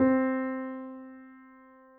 piano_048.wav